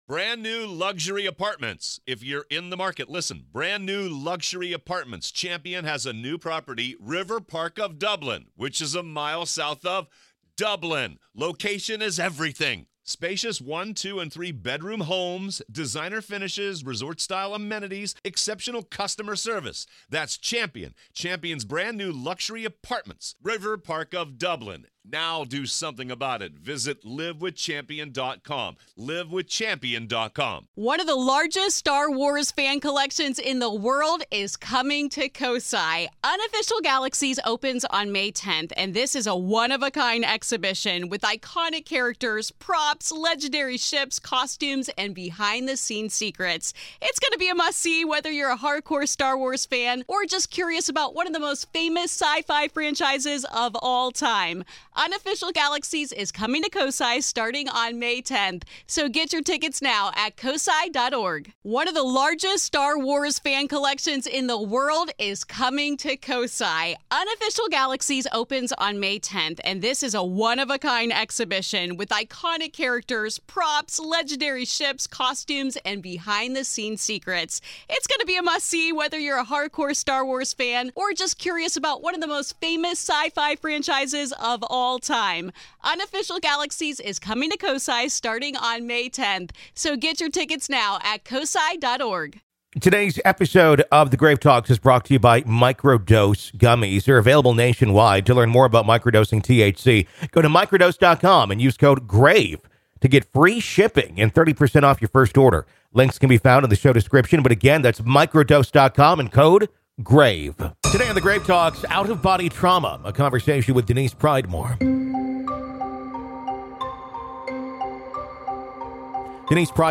Out Of Body Trauma | Interview